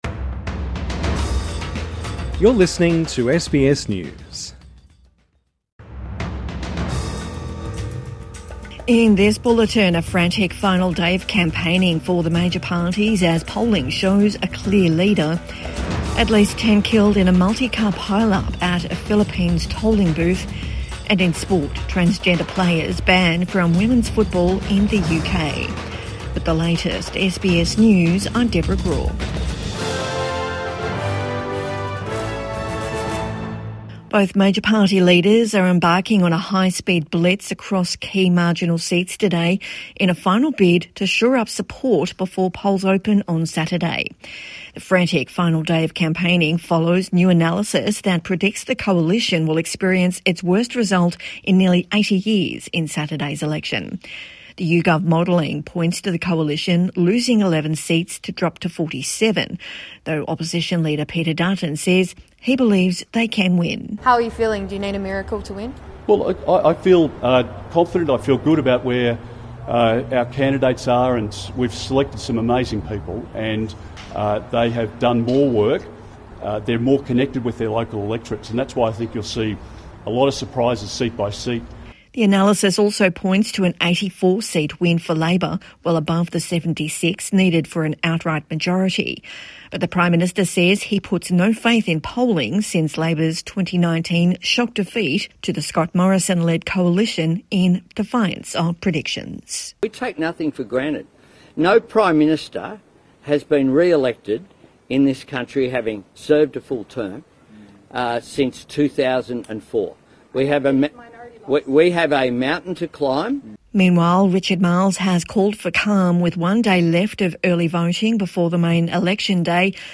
A frantic final day of campaigning | Midday News Bulletin 2 May 2025